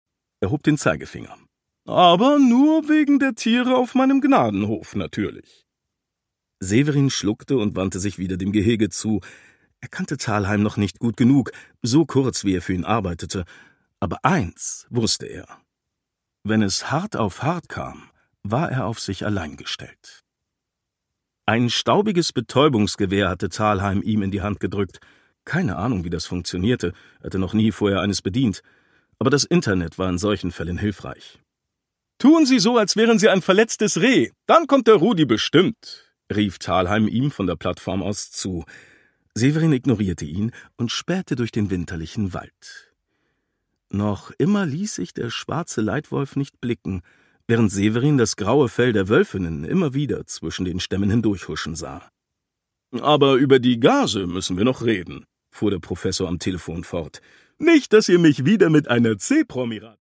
René Anour: Der Doktor und der liebe Mord - Ein Tierarzt-Krimi (Ungekürzte Lesung)
Produkttyp: Hörbuch-Download